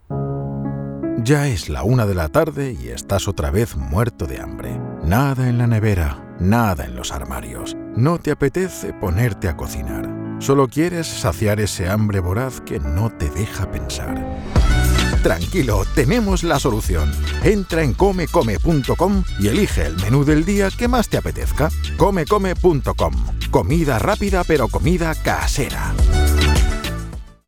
Ankündigungen
Mikrofon: Neumann TLM-103
Im mittleren Alter
Bass